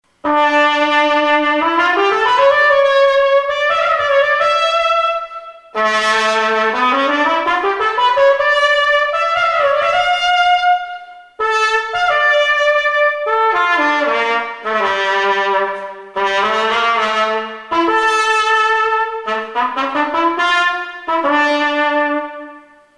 Please pardon the sound quality. I used my Sony camcorder, then ran the sound into my PC.
No editing was done to the sound clips other than adding a little reverb. But excuses aside, here are a few samples of this NY styled 43G belled Bach Strad: